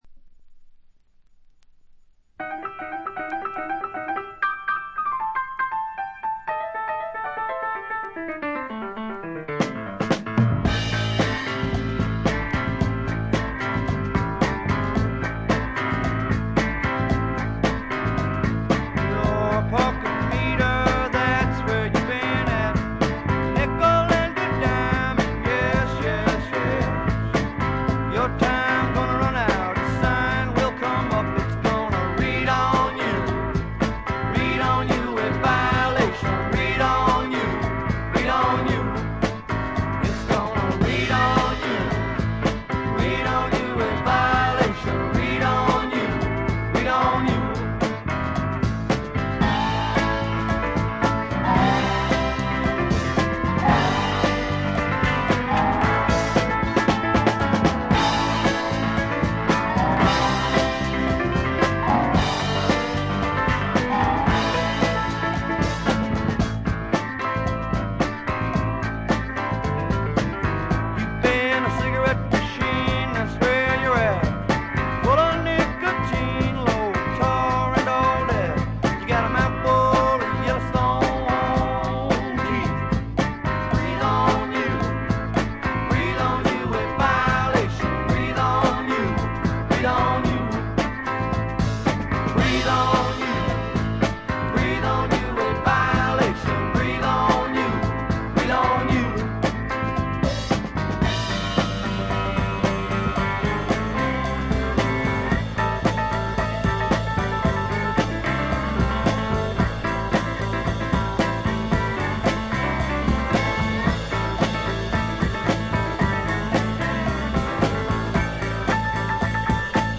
ほとんどノイズ感無し。
ニューヨーク録音、東海岸スワンプの代表作です。
いかにもイーストコーストらしい機知に富んだスワンプアルバムです。
試聴曲は現品からの取り込み音源です。